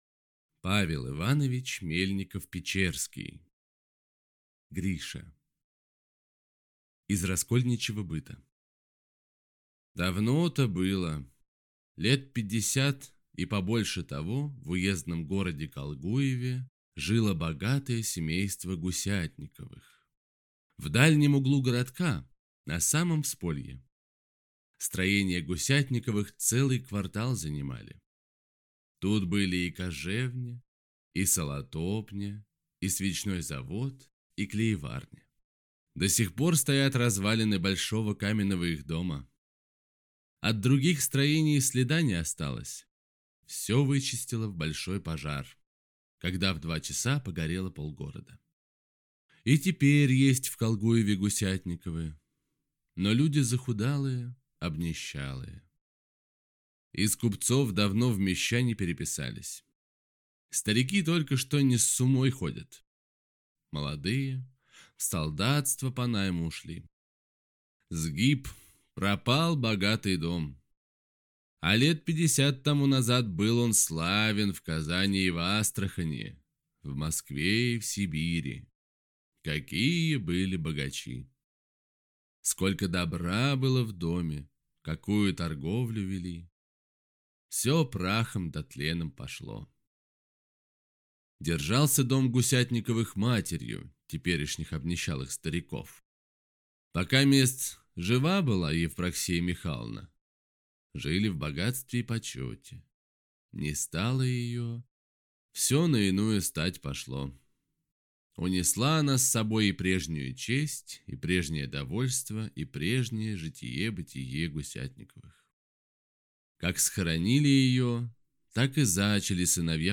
Aудиокнига Гриша